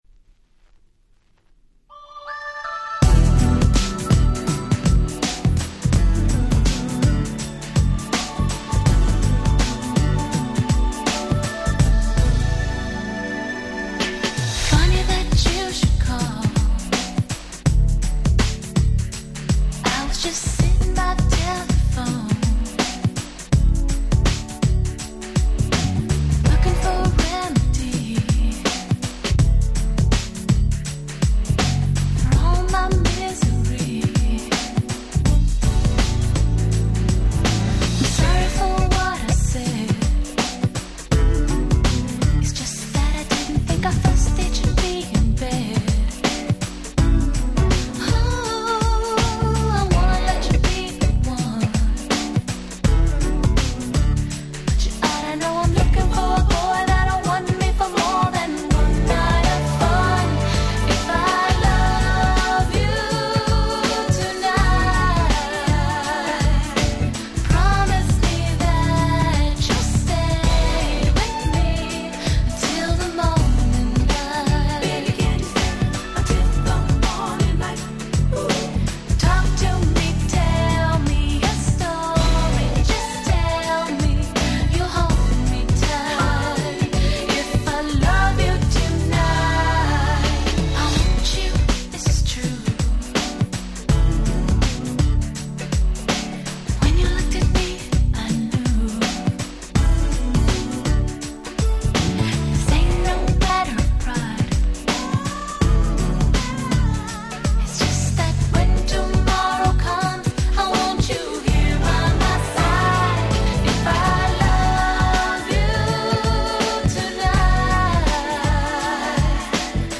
95' Nice Mid R&B !!